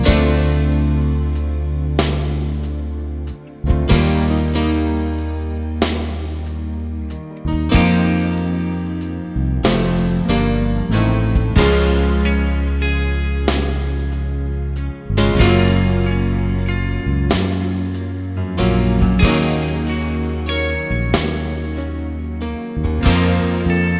Full Backing Vocals In The Intro Pop (1980s) 3:23 Buy £1.50